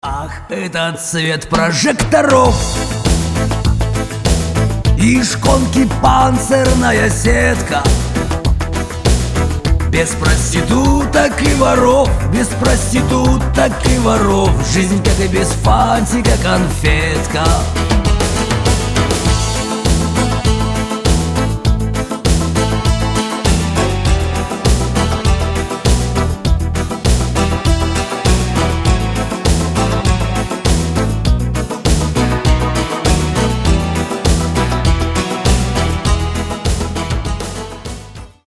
• Качество: 320, Stereo
90-е
блатные
тюремная лирика